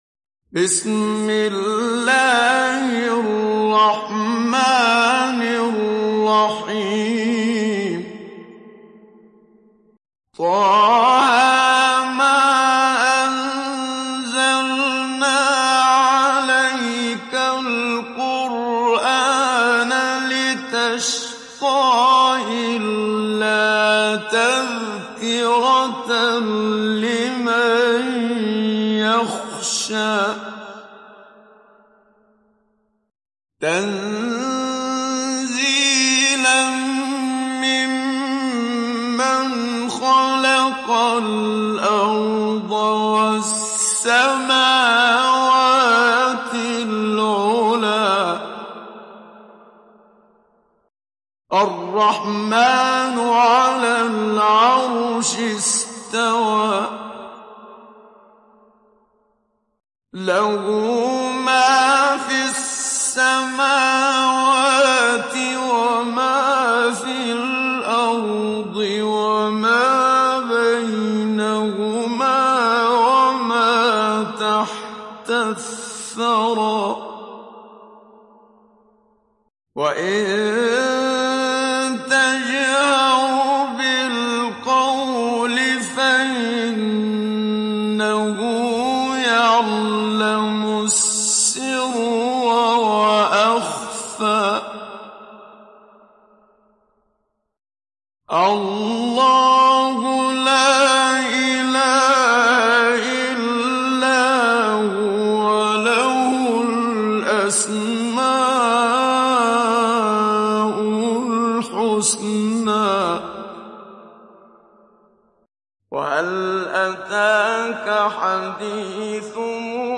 Télécharger Sourate Taha Muhammad Siddiq Minshawi Mujawwad